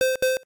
PC access.ogg